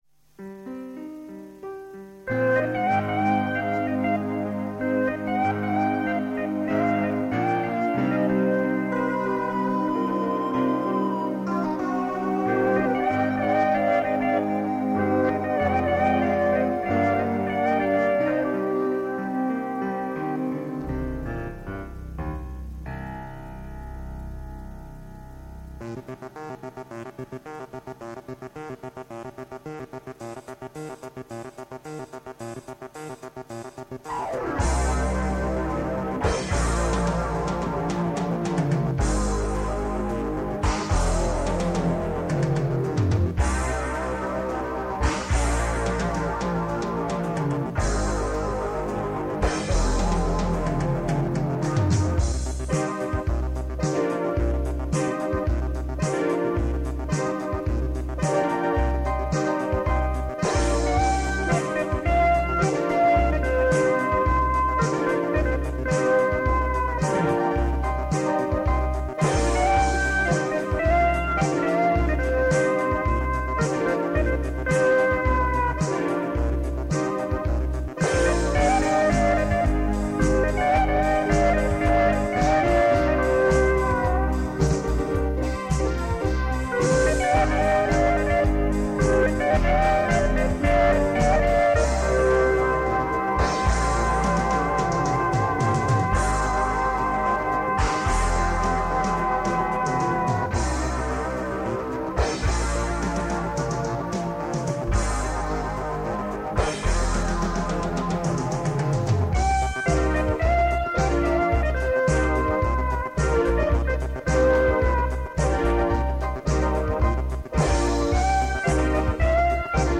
… wo mein Heimstudio steht.
Grenzen waren mir denn auch nur beim RAM und der Qualität des 4-Spur Kassetten-Recorders gesetzt.
Die Drums waren dabei allerdings immer am Live-Spiel und Live -Sound orientiert, da ich mich gerade mit Schlagzeugspielen befasste, wodurch die typischen 80er Midi-Drums Sounds bei damaligen meinen Geschichten kaum oder nicht zum Tragen kamen.